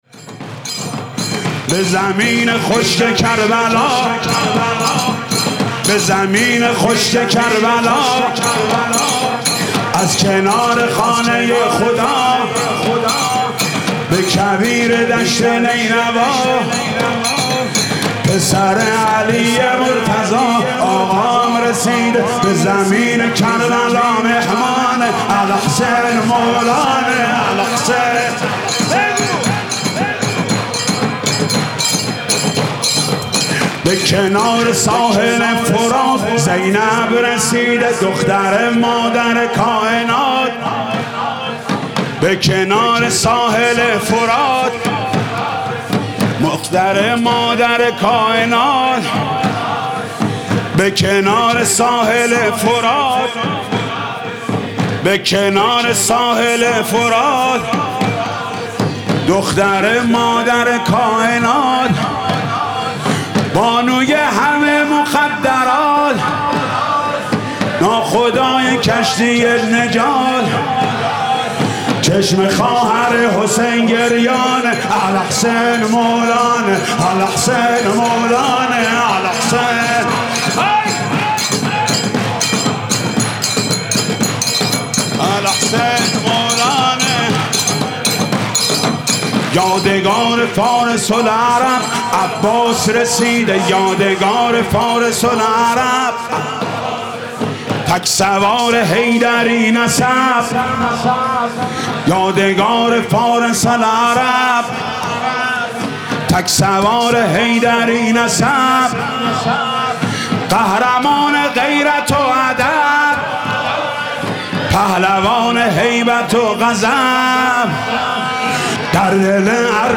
مداحی شب دوم محرم